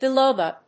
biloba.mp3